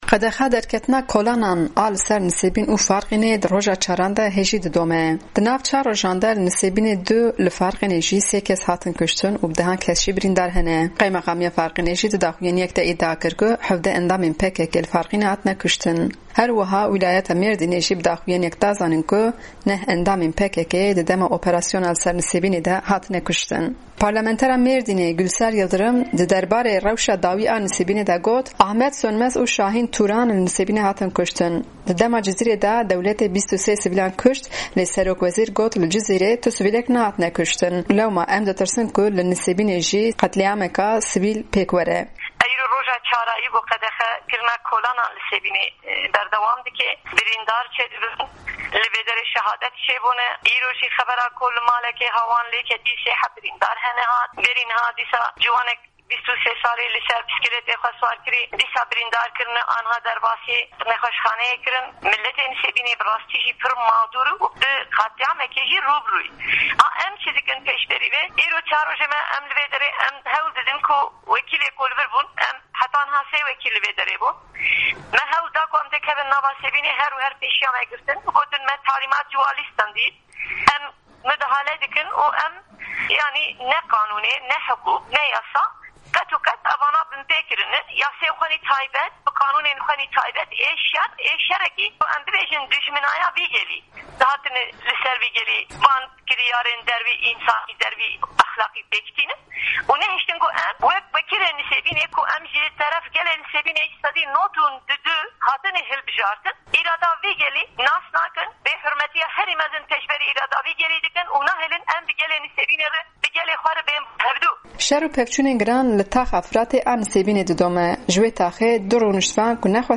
Ji wê taxê du rûniştvan ji Dengê Amerîka re behsa rewşa xwe kirin.